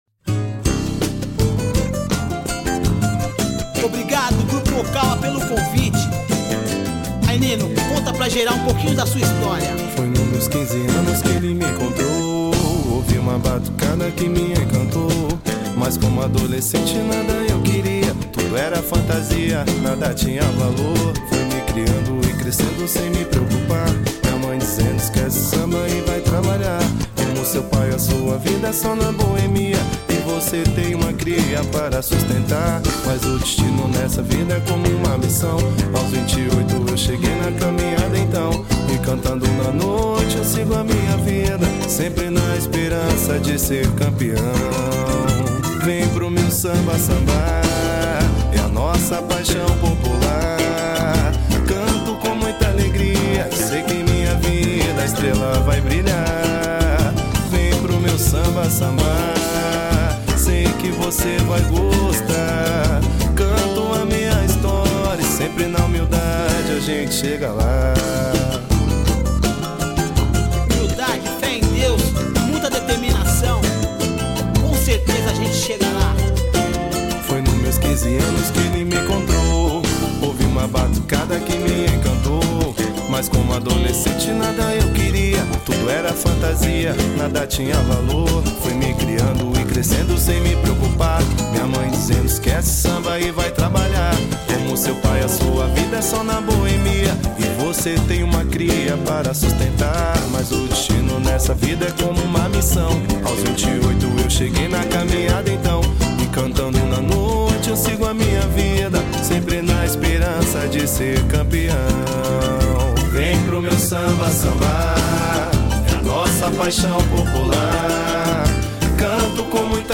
EstiloSamba